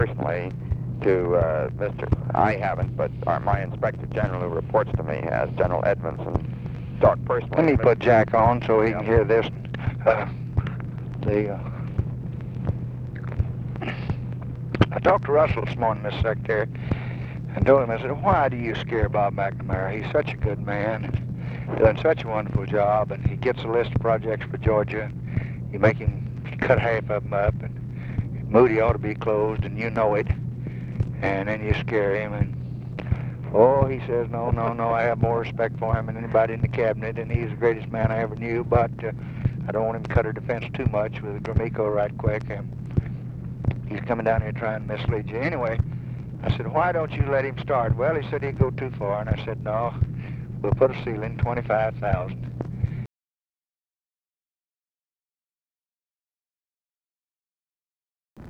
Conversation with ROBERT MCNAMARA, December 9, 1964
Secret White House Tapes